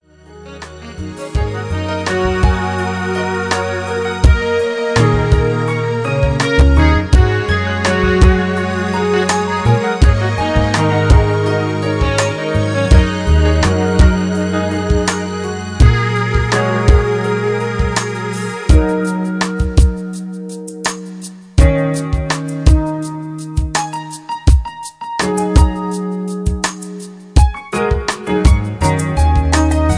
Instrumental Inspirational